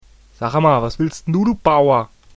Deutsche Sprecher (m)